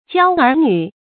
骄儿騃女 jiāo ér bā nǚ 成语解释 指天真幼稚的儿童。